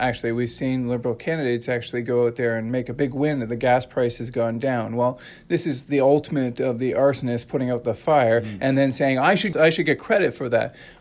We chatted with Lawrence at his campaign office in Cobourg to get a sense of the main issues he is hearing at the door.